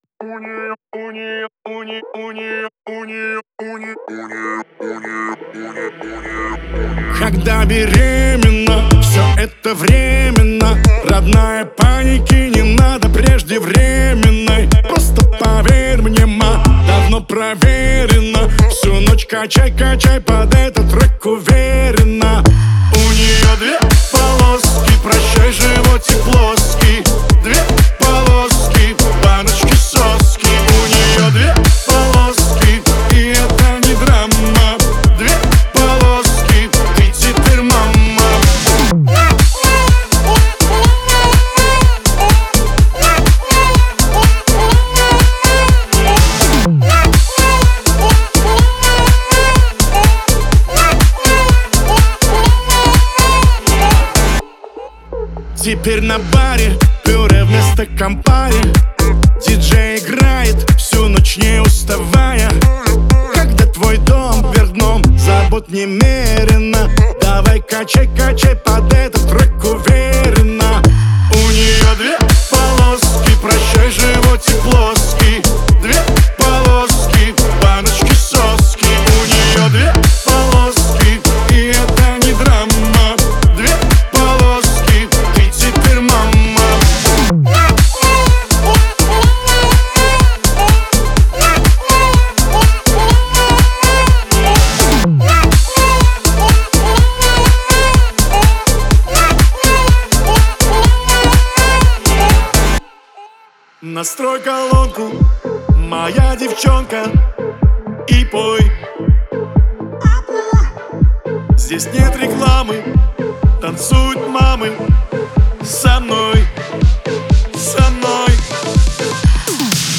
яркая и энергичная песня